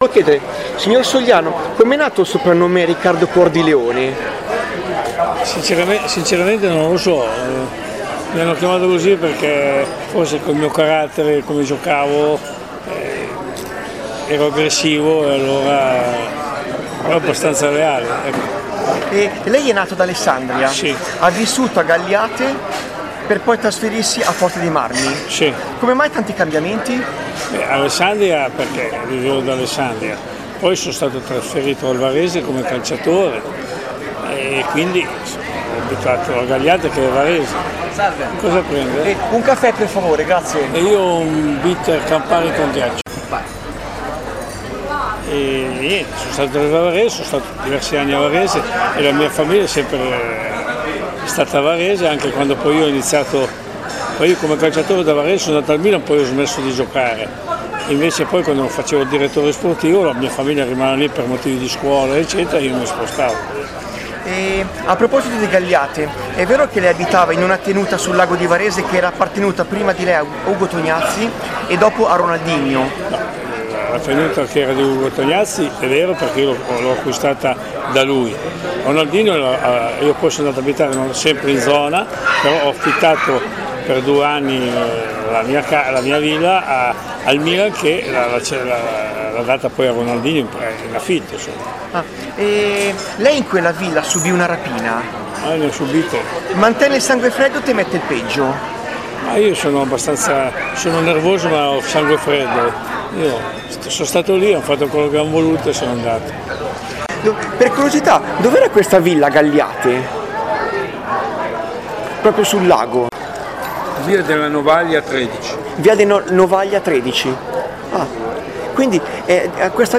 30 settembre 2018, Forte dei Marmi (LU)